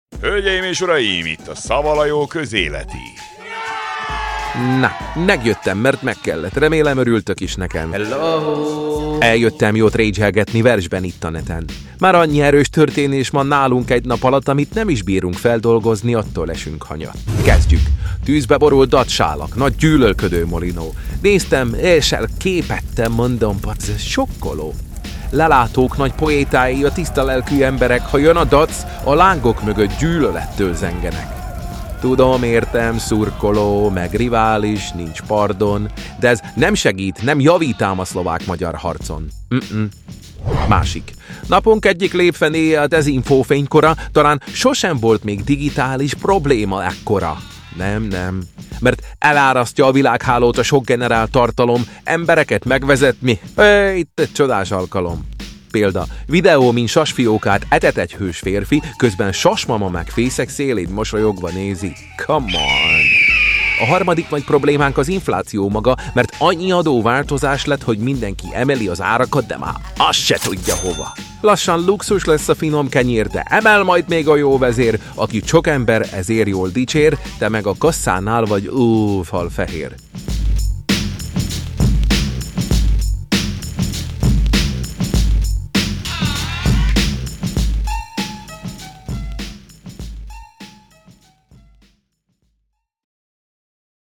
Zene: Del Tha Funkee Homosapien – Ahonetwo, Ahonetwo (Instrumental)